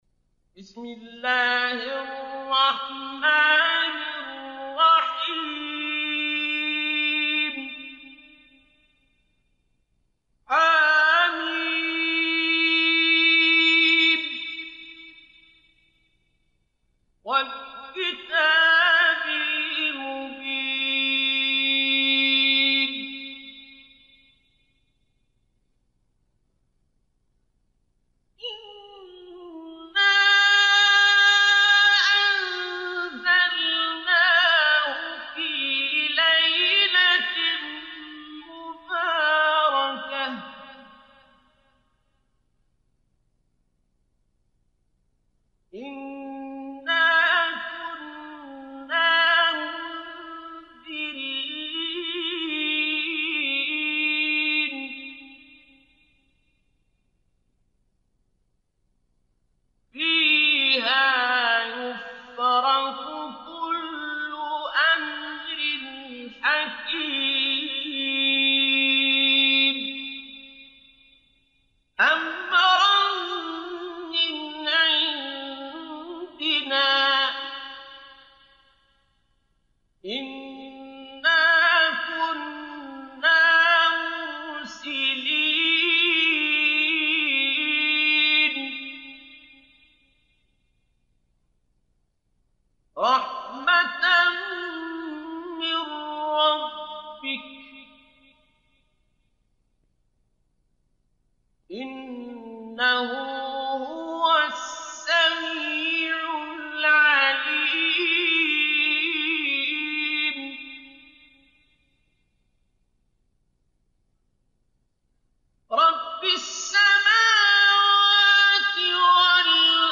سورة الدخان - الختمة المجودة المنوعة (برواية حفص عن عاصم) - طريق الإسلام
سورة الدخان - الختمة المجودة المنوعة (برواية حفص عن عاصم) (صوت - جودة عالية